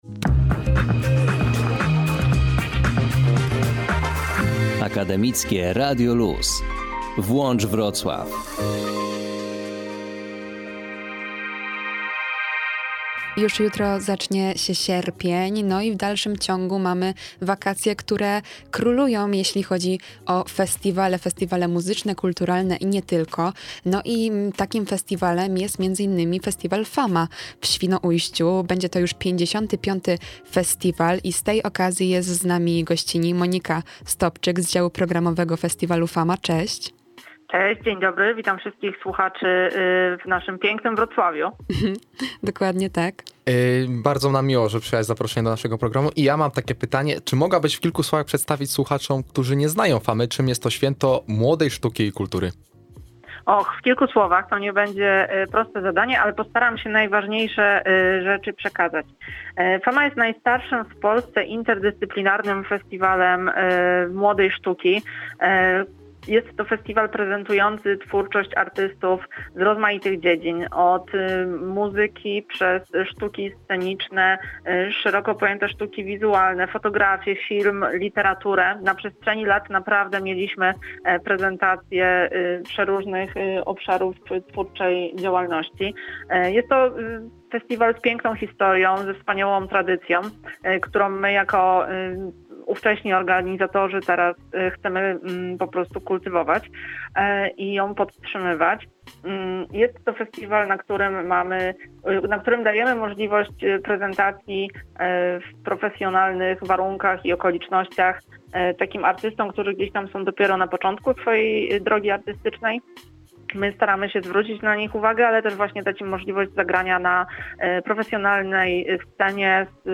O tym, co będzie się działo w sierpniu w Świnoujściu, rozmawialiśmy w ramach naszej wakacyjnej audycji Włącz Wrocław.